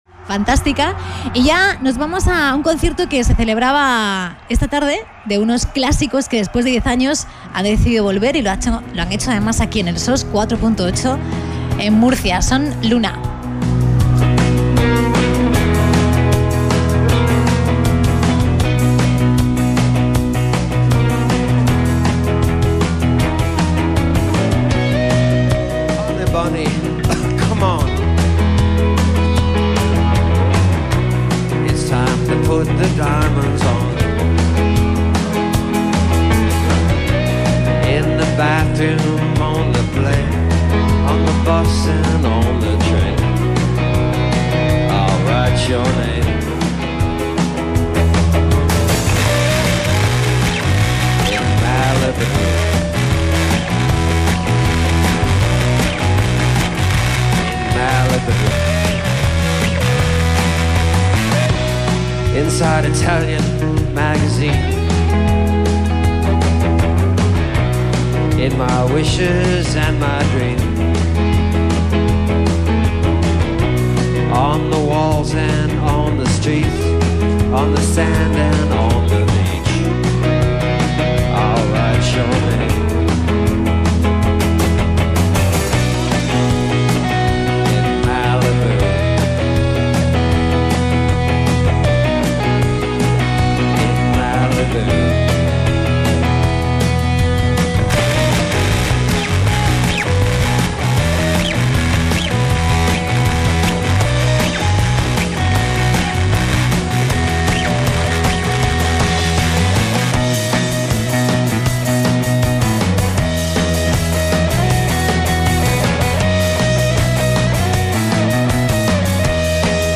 Live at SOS Festival 4.8 – Murcia, Spain
Indie/Dream Pop